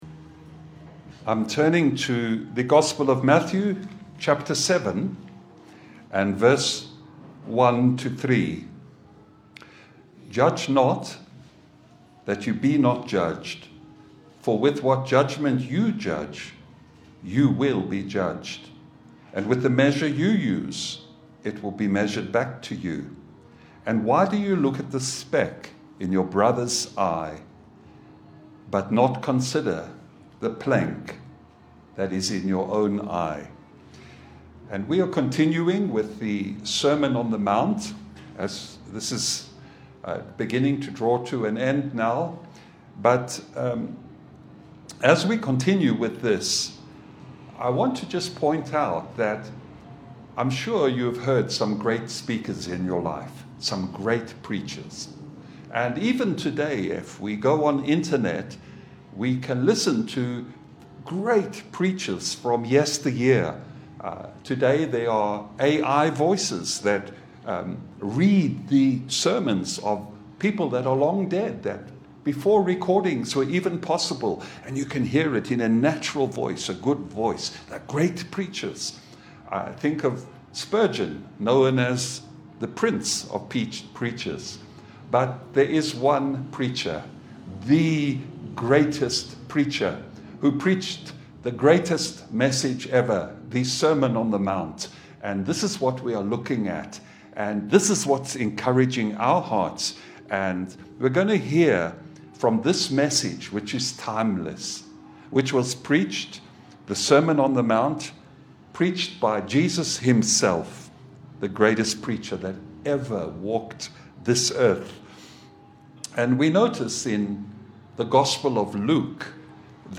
Matthew 7:1 Service Type: Sunday Bible fellowship « James 5.